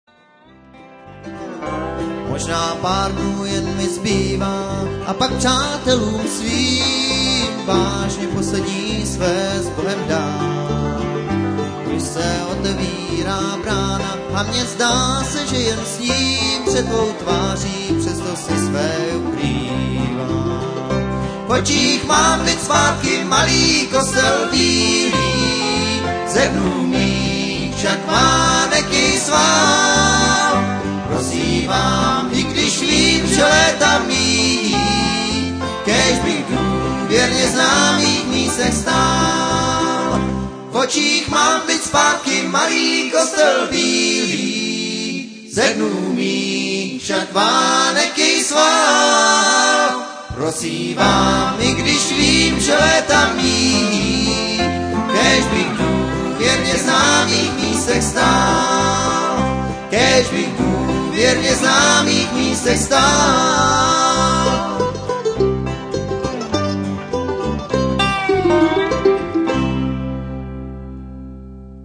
banjo
dobro
mandolin